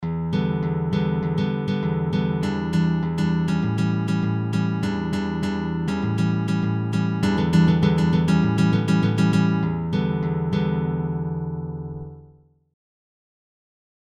BY PAUL GILBERT > Brazilian fingerstyle patterns
Brazilian+fingerstyle+patterns.mp3